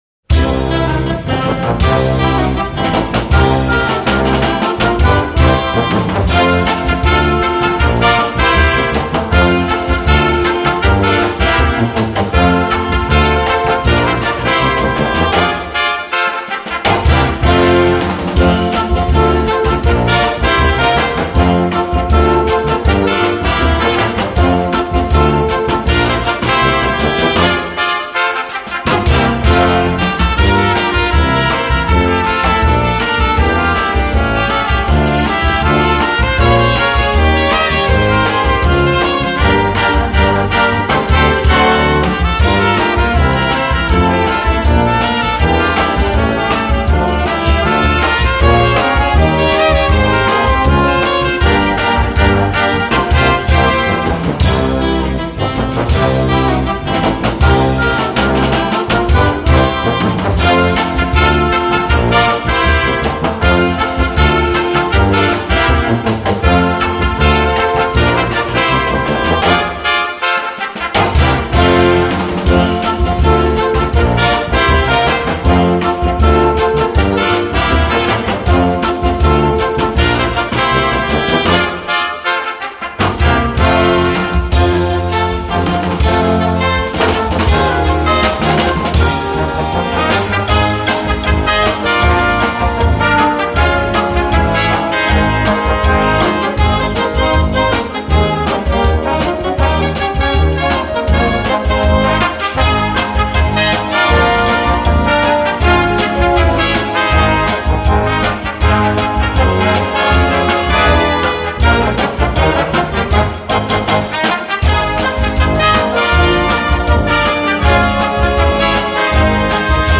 Noten für Blasorchester, oder Brass Band.
• Trumpet Section & Concert Band